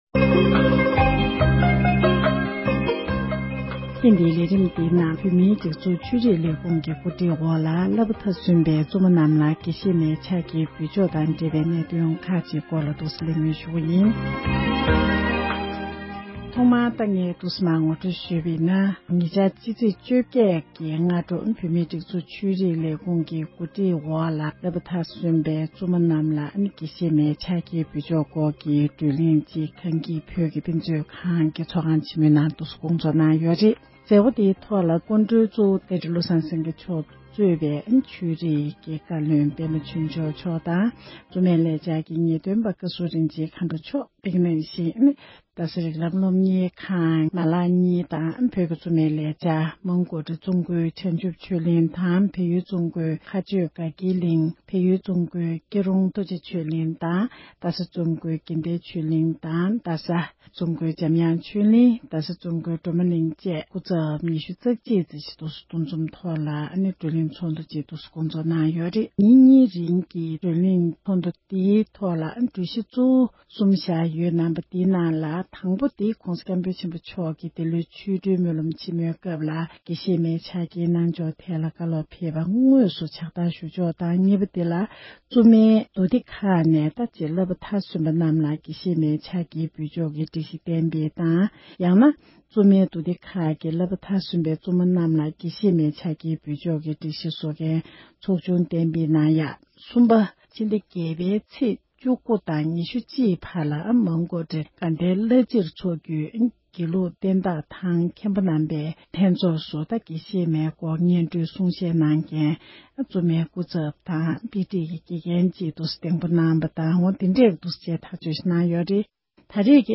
འབྲེལ་ཡོད་མི་སྣར་གནས་འདྲི་ཞུས་ཏེ་གནས་ཚུལ་ཕྱོགས་སྒྲིགས་ཞུས་པ་ཞིག་གསན་རོགས་ཞུ༎